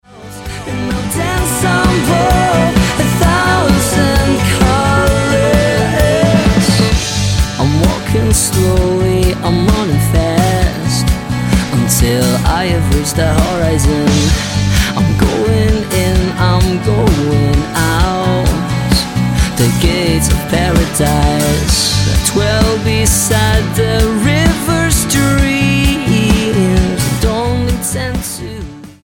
atmospheric rock
Style: Rock